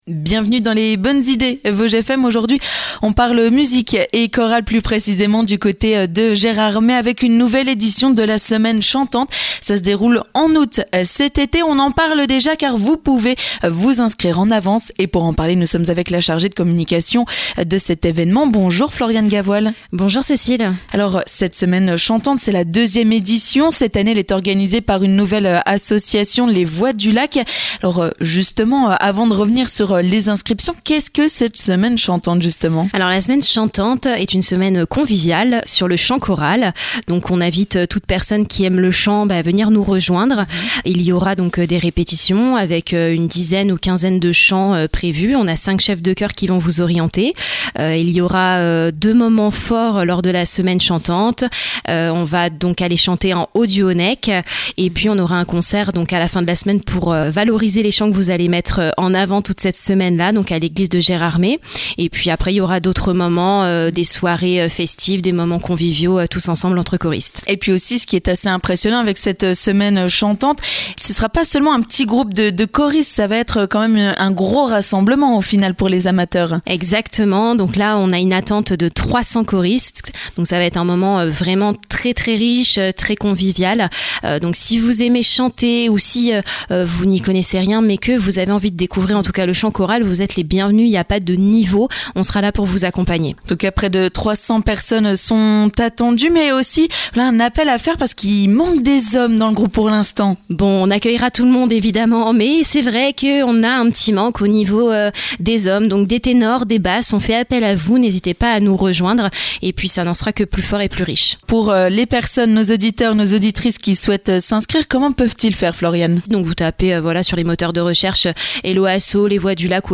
accueille en studio